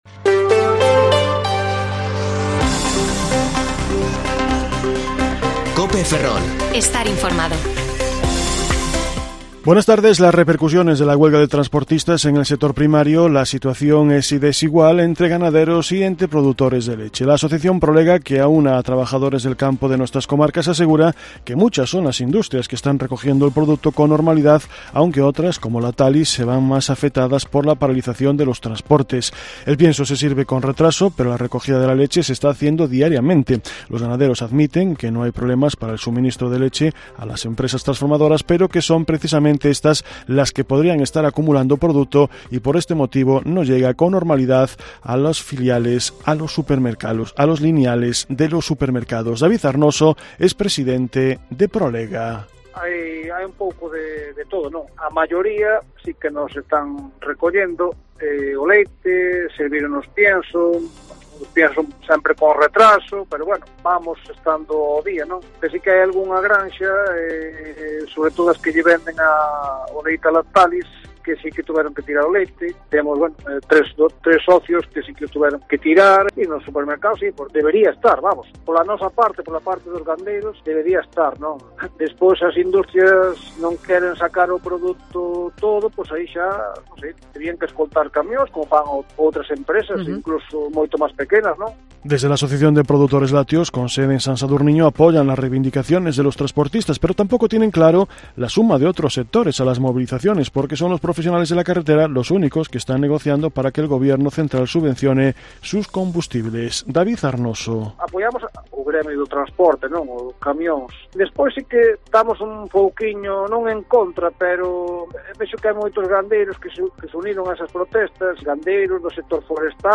Informativo Mediodía COPE Ferrol 24/3/2022 (De 14,20 a 14,30 horas)